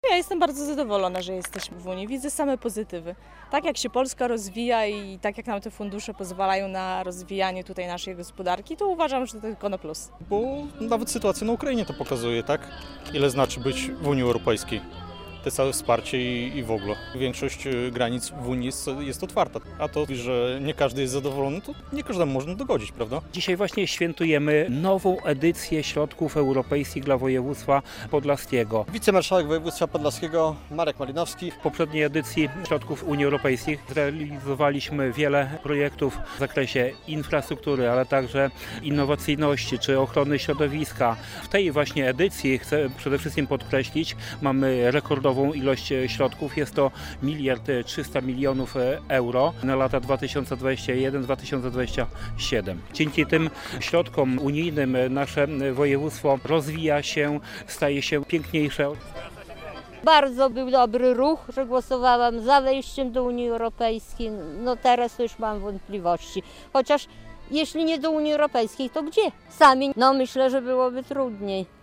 Piknik europejski - relacja